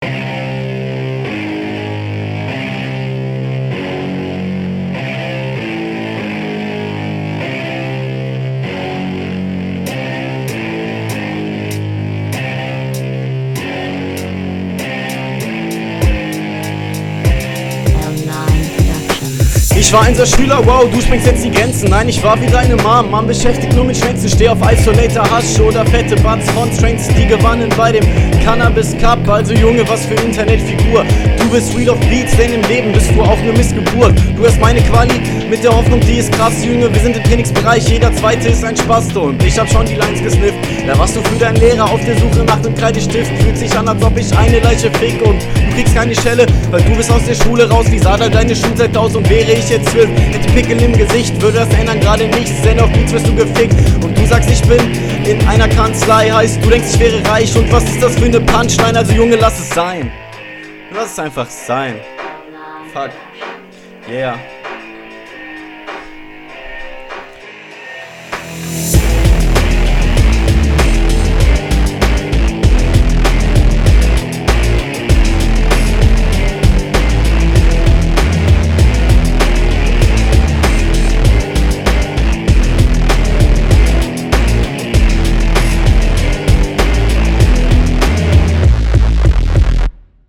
Schön das ich Mal hier stimme höre und ich finde der flow ist nicht so …